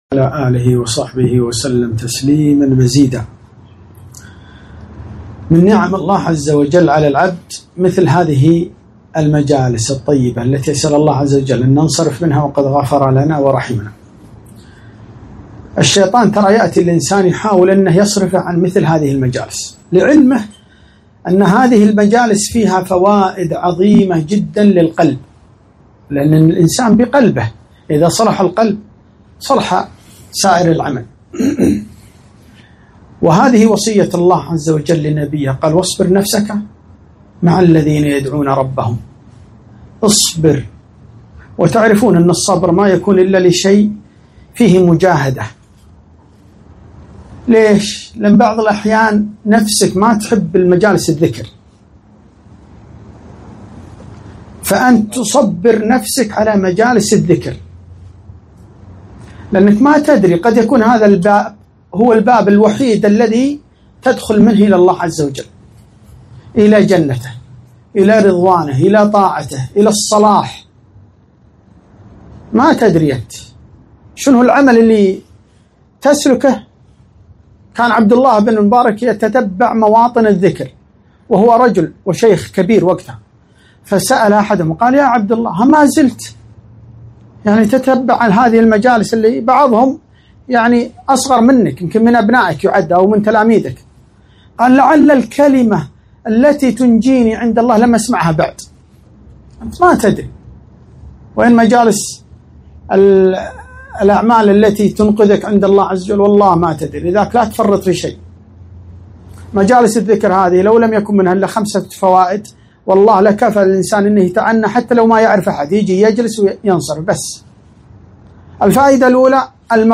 محاضرة - وقفات مع قوله تعالى: ( وهدوا إلى الطيب من القول )